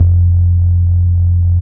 Menacing Bass 1 (JW2).wav